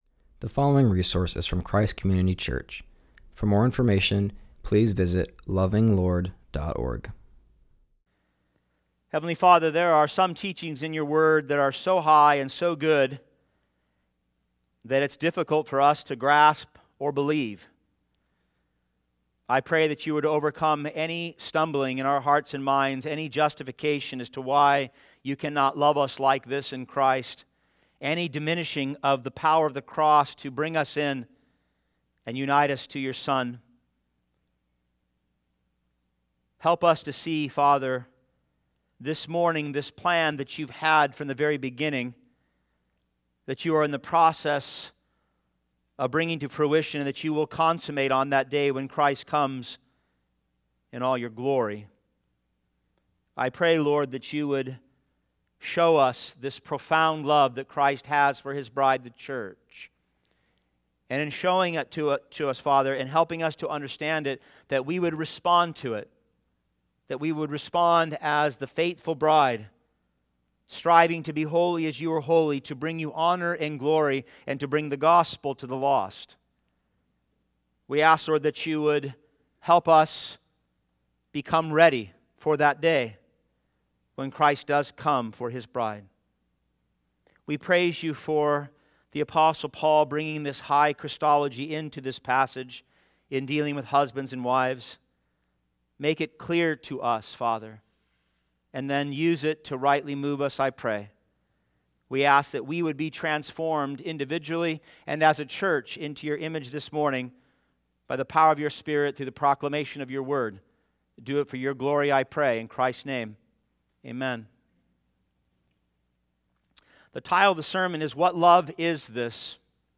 continues our series and preaches from Ephesians 5:22-33.